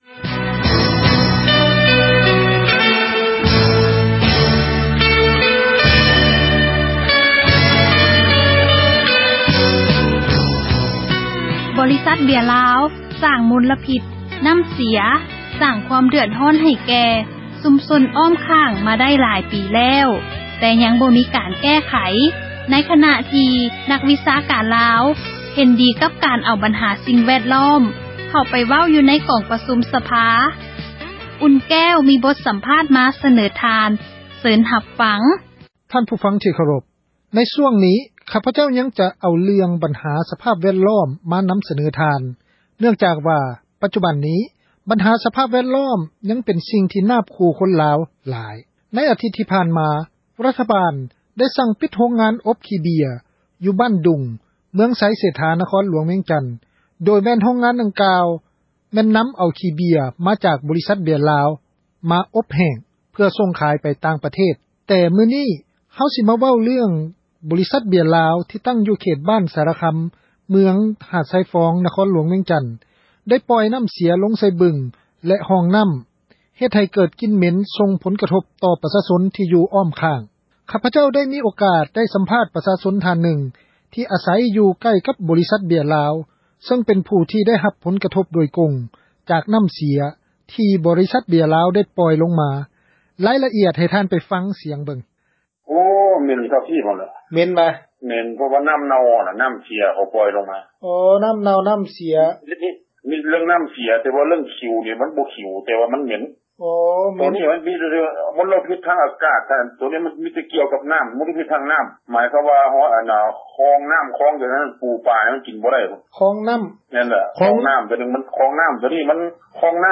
ມີບົດສຳພາດ ມາສະເໜີ ທ່ານ.